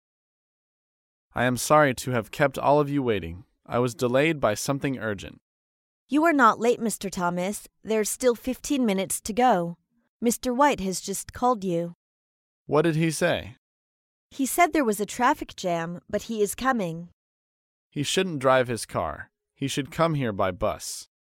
高频英语口语对话 第112期:迟到致歉(1) 听力文件下载—在线英语听力室
在线英语听力室高频英语口语对话 第112期:迟到致歉(1)的听力文件下载,《高频英语口语对话》栏目包含了日常生活中经常使用的英语情景对话，是学习英语口语，能够帮助英语爱好者在听英语对话的过程中，积累英语口语习语知识，提高英语听说水平，并通过栏目中的中英文字幕和音频MP3文件，提高英语语感。